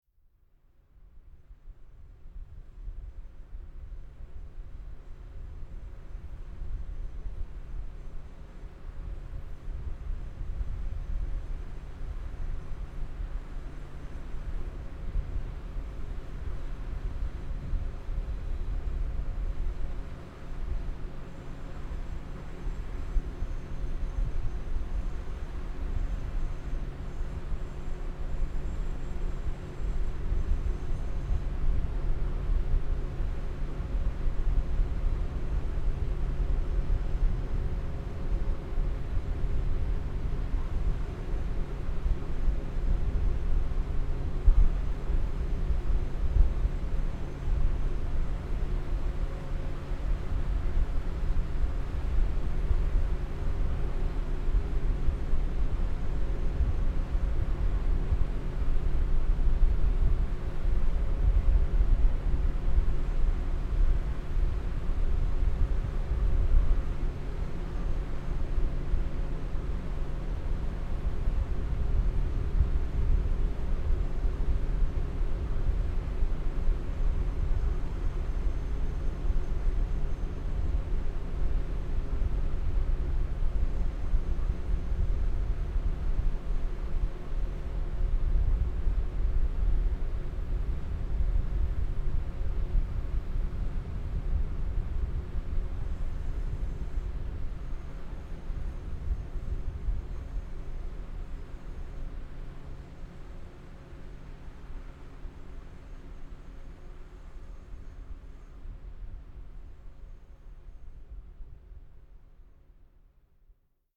Stuttgart 21 XV: Ventilator at Tunnel Construction at Ulmer Straße
• construction
• Ventilator
• underground tunnel